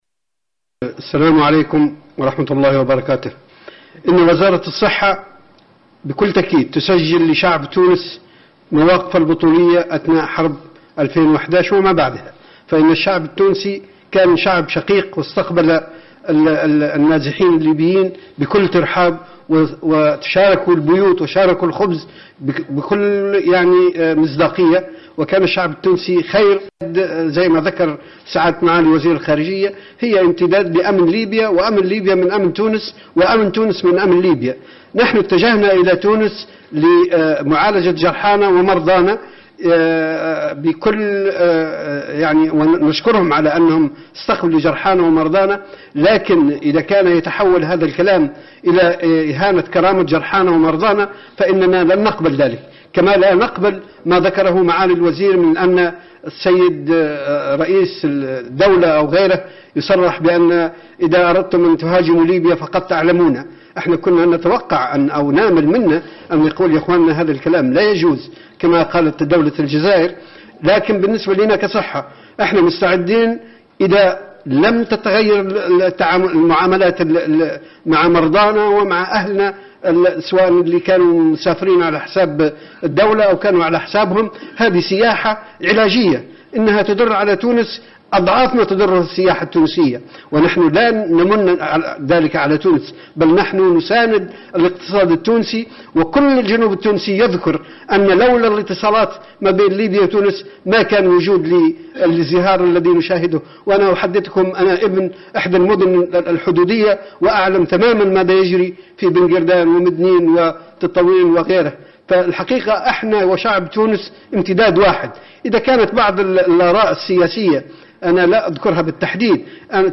الندوة الصحفية كاملة